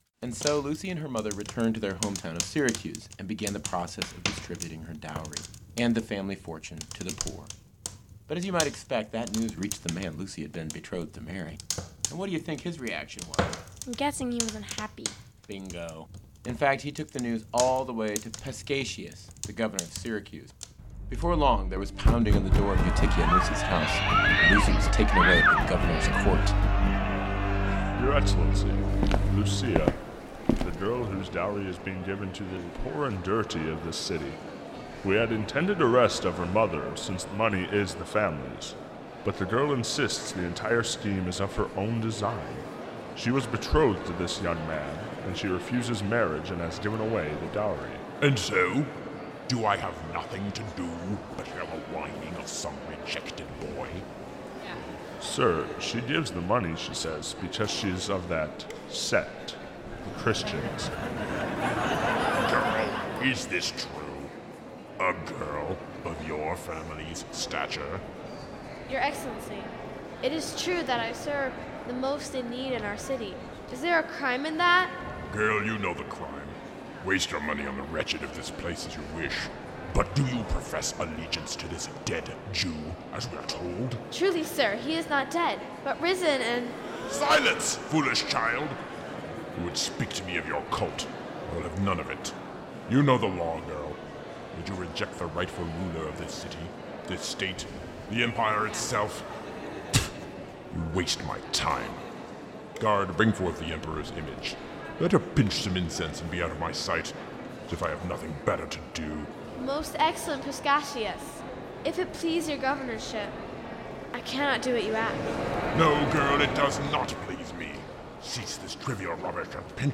Preview Sample - St. Lucy's feast - GOLDEN KEY CATHOLIC AUDIO DRAMA
The-Golden-Key-St-Lucy-sample-scene-the-governors-palace.mp3